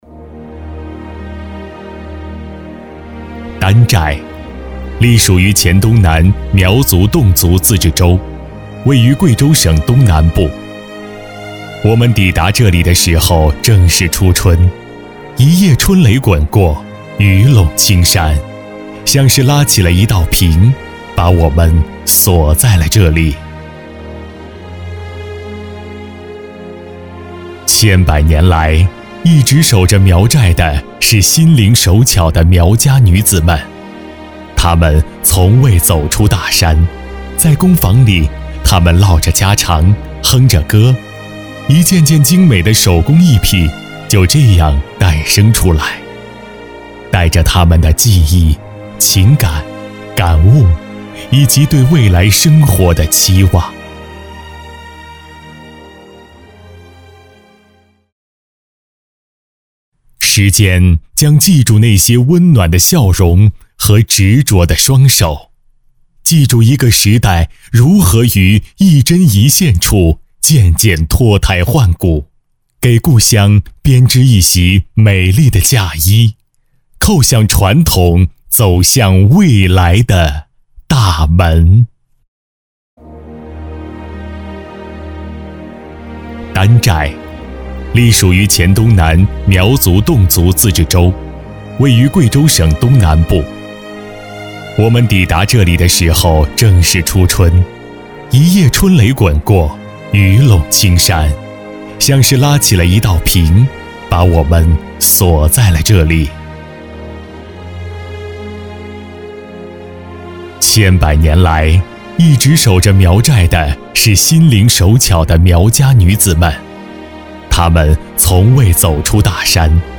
职业配音员全职配音员浑厚大气
• 男S331 国语 男声 专题-指尖奇迹（大气 沉稳） 大气浑厚磁性|沉稳